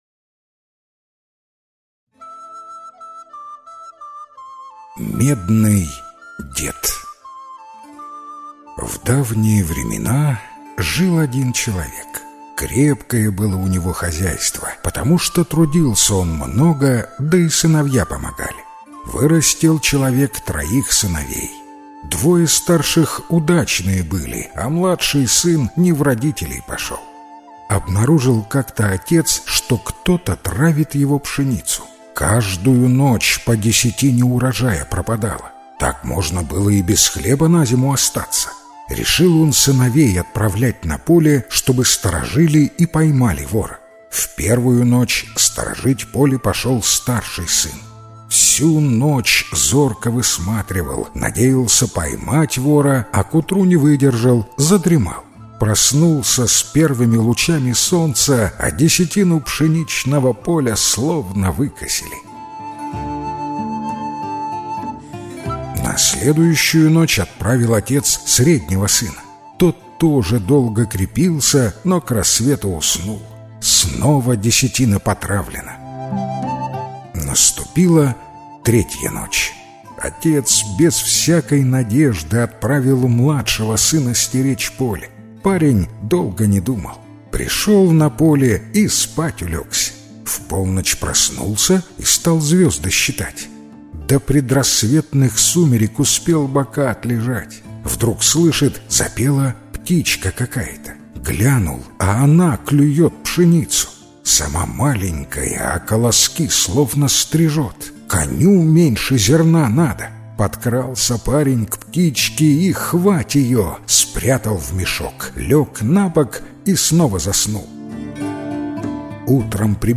Медный дед - белорусская аудиосказка. Повадился кто-то пшеницу у мужика на поле воровать. Посла мужик своих сыновей ночью поле стеречь.